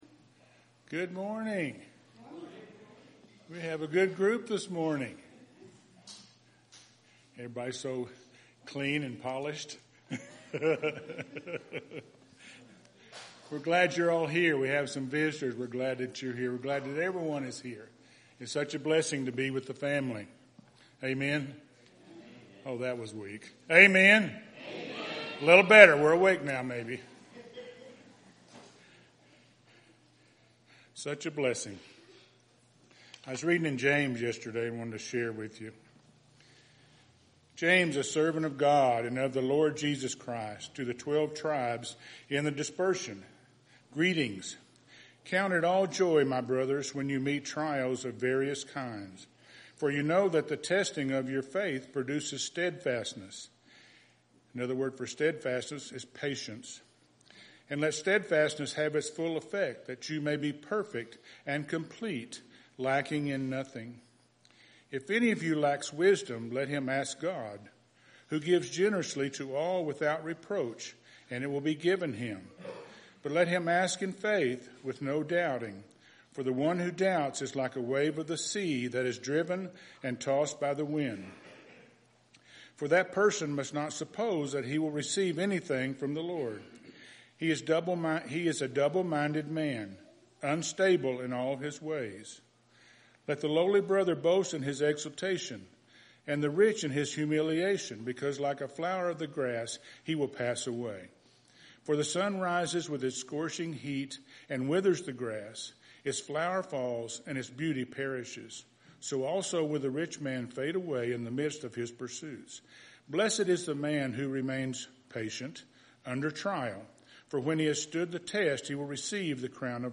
September 20th – Sermons